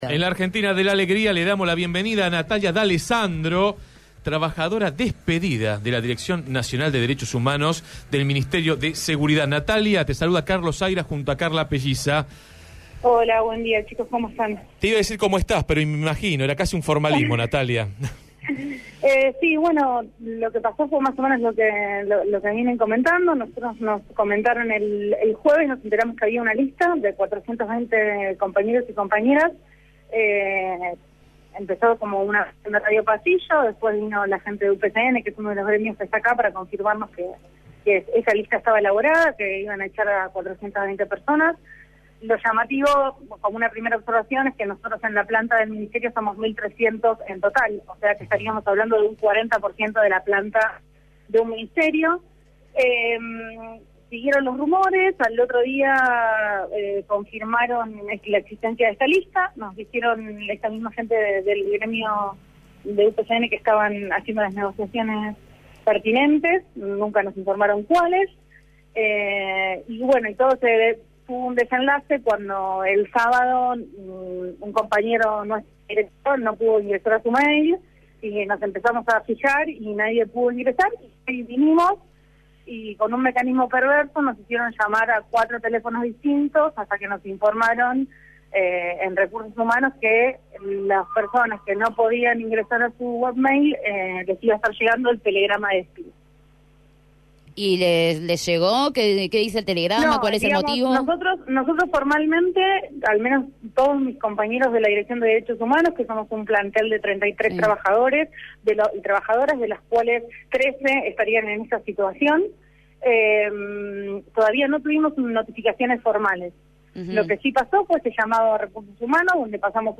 trabajadora despedida de la Dirección Nacional de Derechos Humanos del ministerio de Seguridad